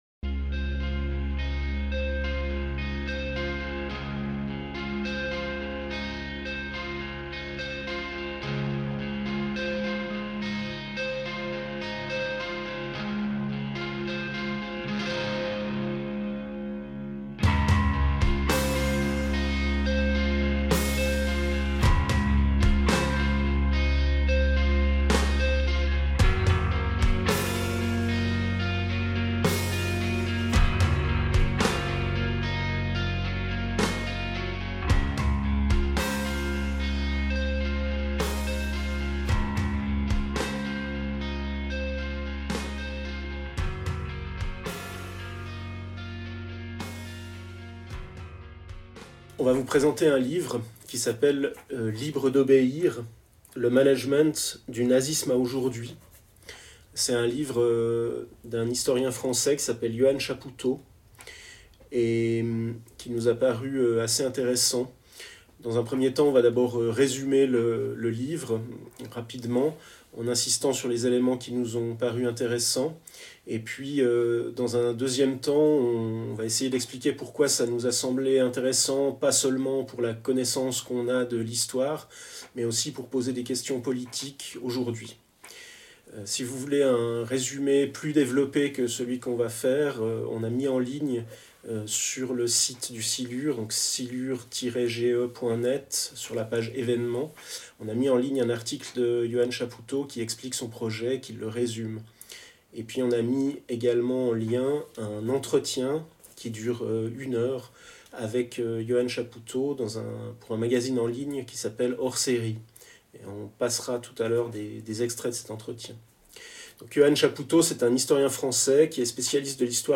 Première émission de Radio Silure, diffusée le 28 mars 2020, en remplacement de la présentation publique, annulée pour des raisons sanitaires.